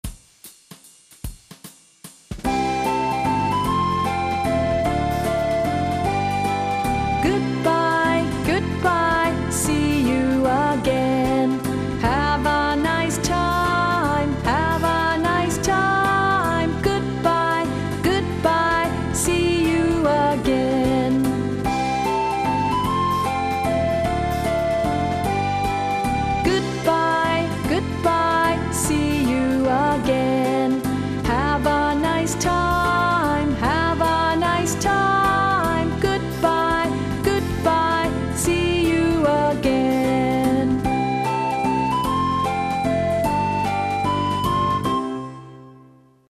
トピックにマッチした歌や英語圏でポピュラーな英語の歌をお届けします。